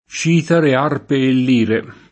©&tara]: citare, arpe e lire [